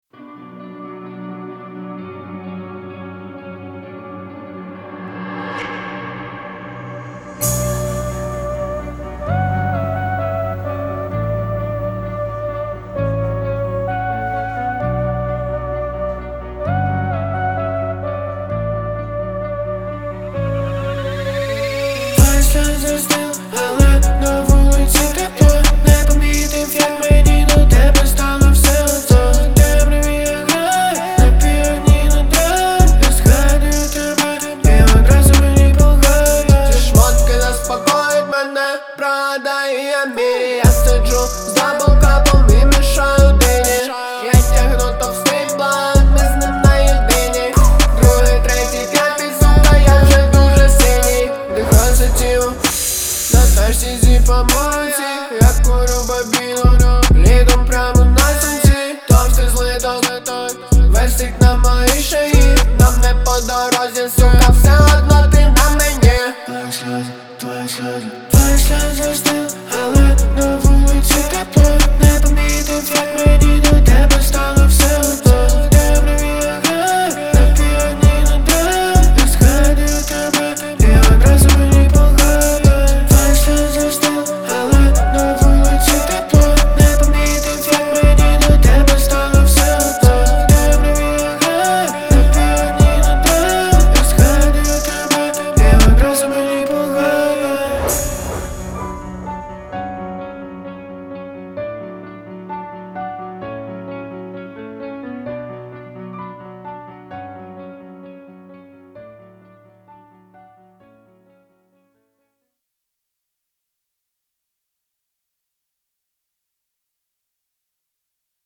• Жанр: Pop, Rap